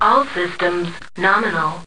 Polaris/sound/mecha/nominal.ogg